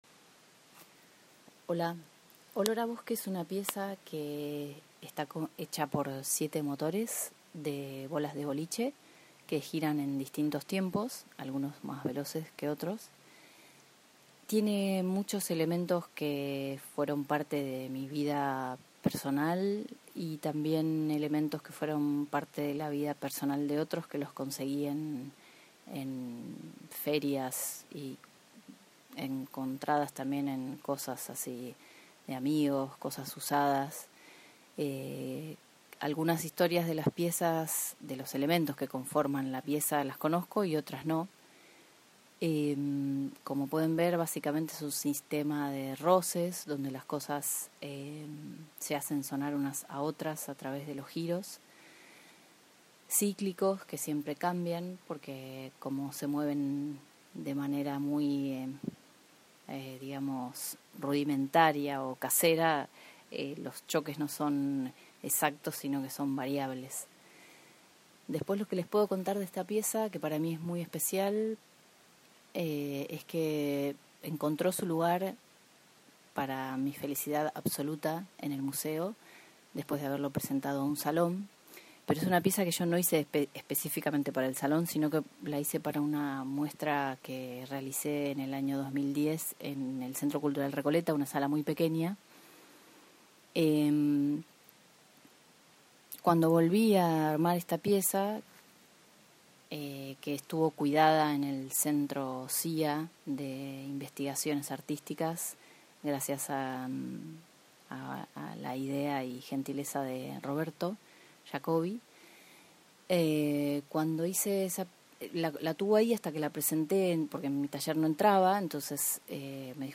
En estos enlaces vas a encontrar algunas de esas respuestas desde la voz de algunos de los artistas.